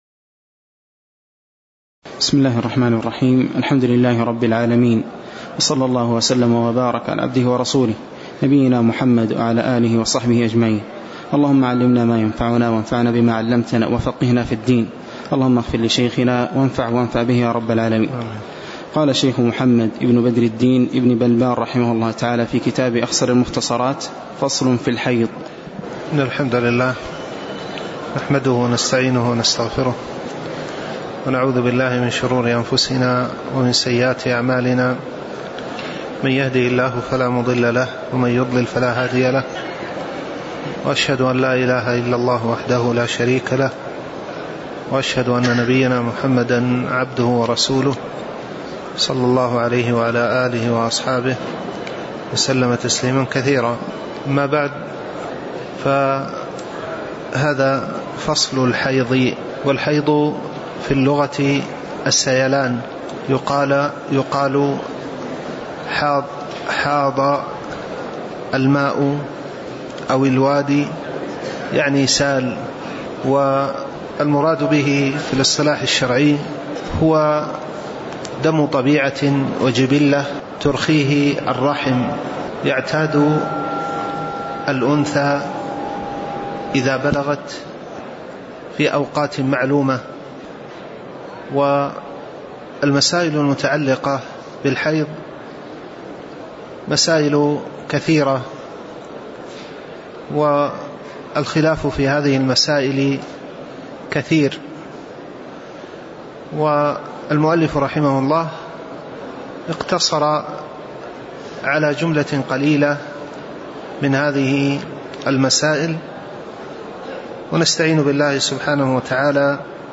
تاريخ النشر ٢٥ ربيع الأول ١٤٣٩ هـ المكان: المسجد النبوي الشيخ